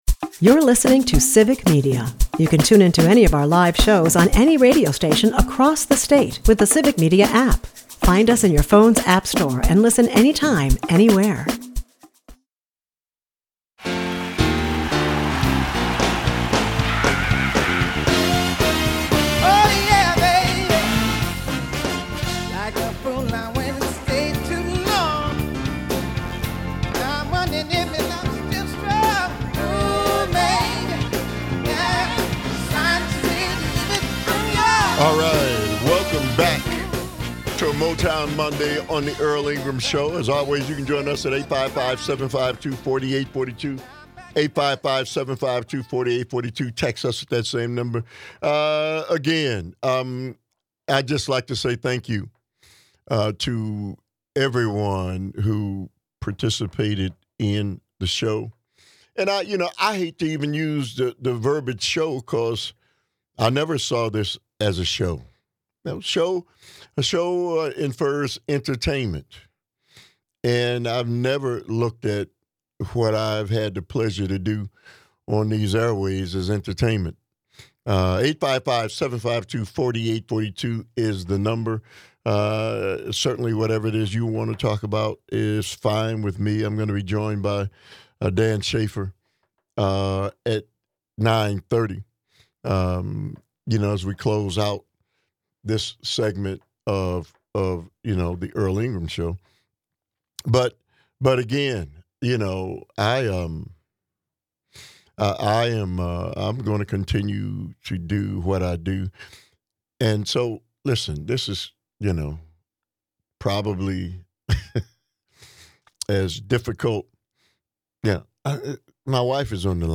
In his emotional farewell, listeners call in to commend his honesty and influence.